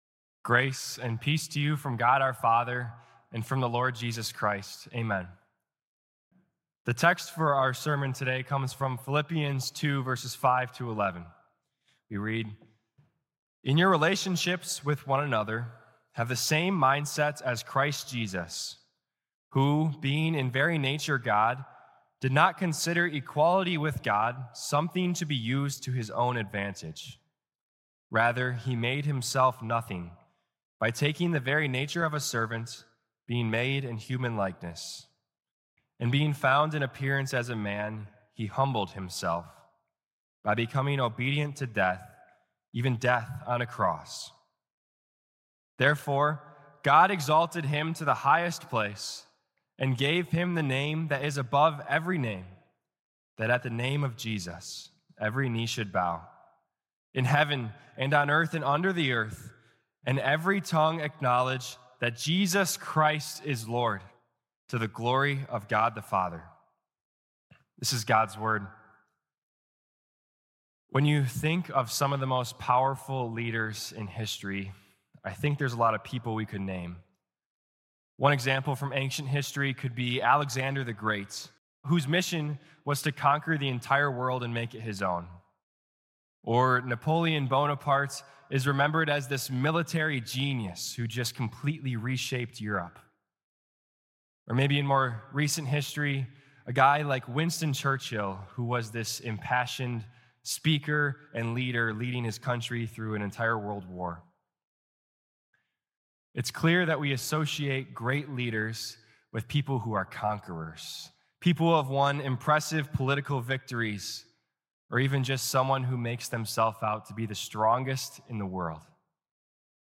Weekly Sermons from St. Marcus Lutheran Church, Milwaukee, Wisconsin